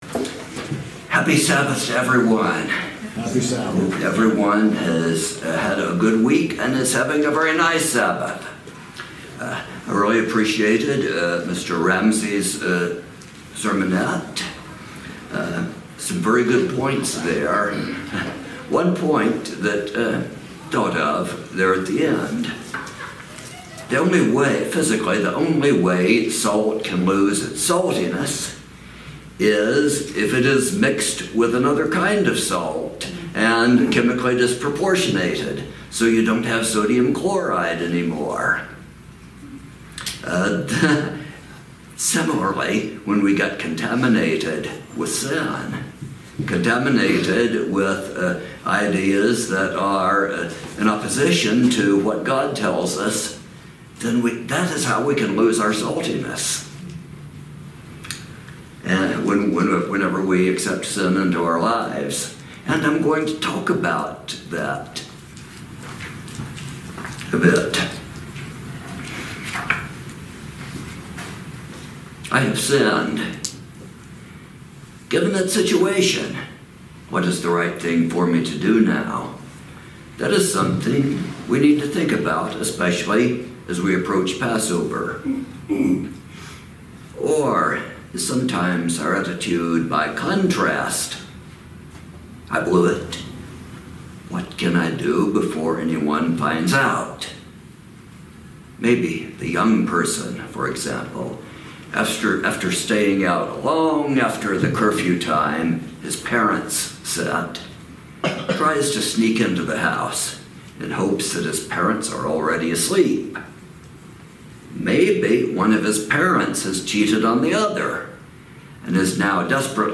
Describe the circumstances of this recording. Given in Houston 9/17/2005 as split sermon